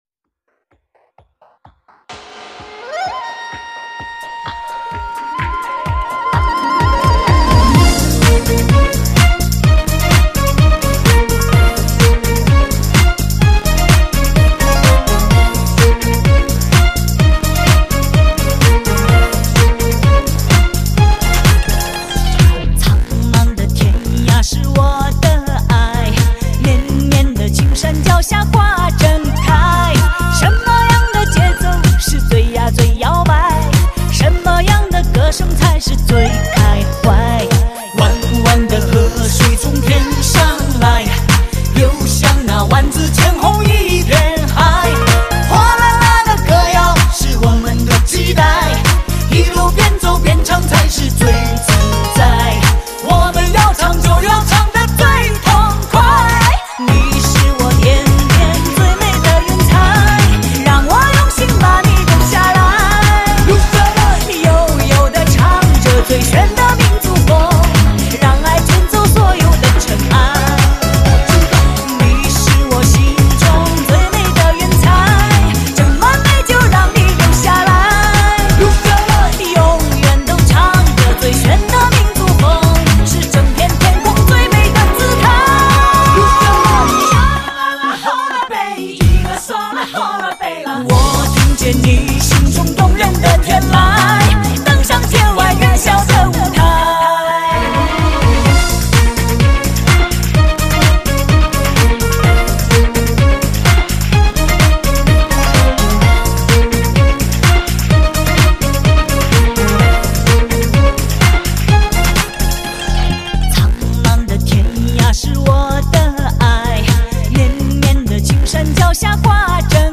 迪斯科舞曲版
舞曲版的别有一番情趣